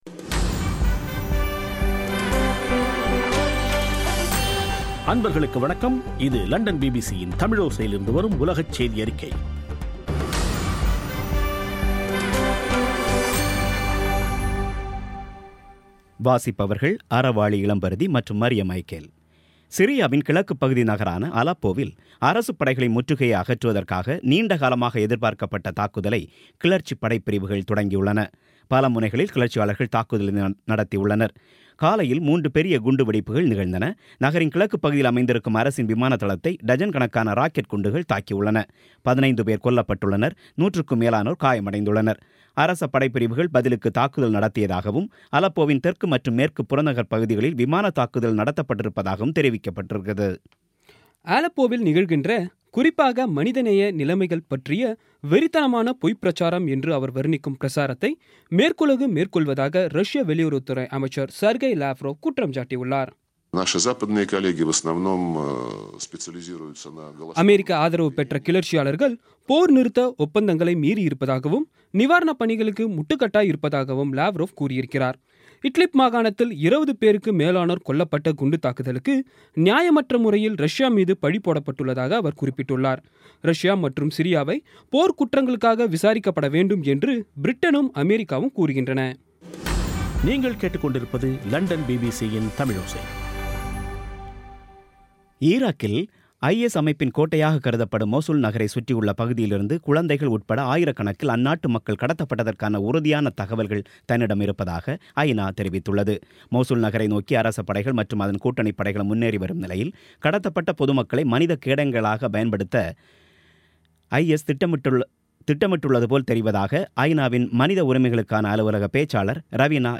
இன்றைய (அக்டோபர் 28ம் தேதி ) பிபிசி தமிழோசை செய்தியறிக்கை